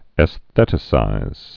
(ĕs-thĕtə-sīz)